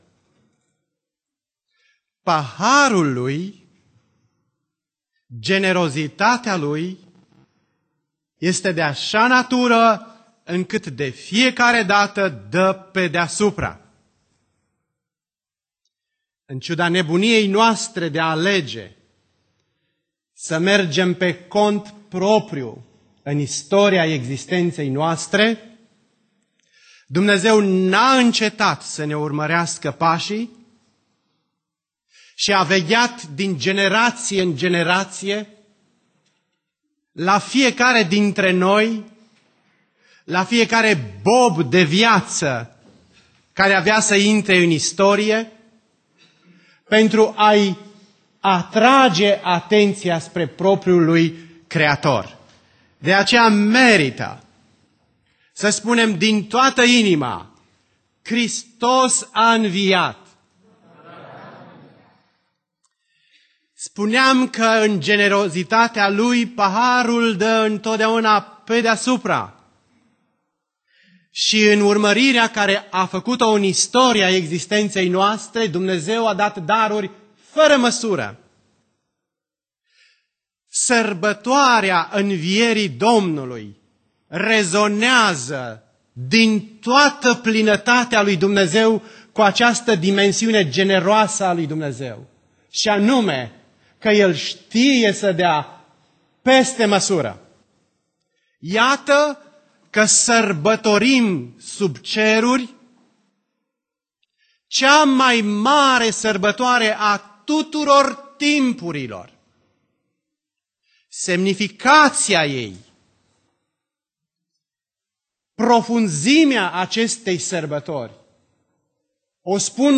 Predica Evanghelia dupa Ioan cap 21